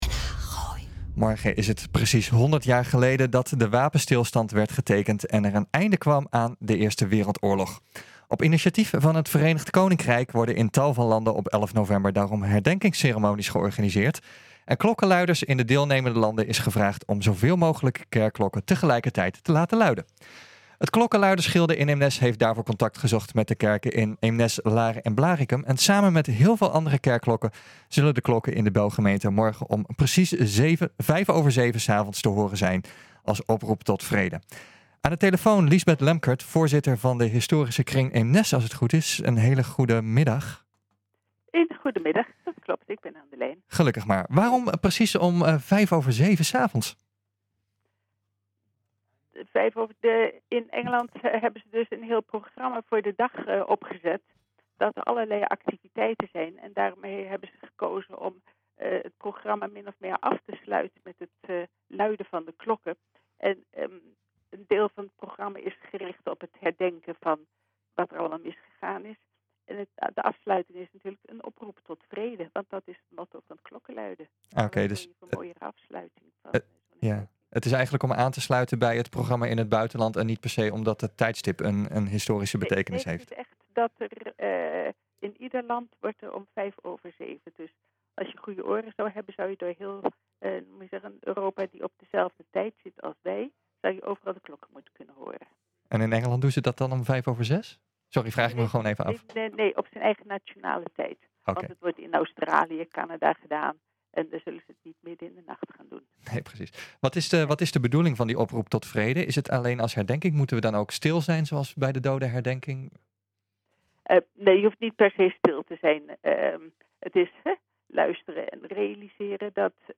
Aan de telefoon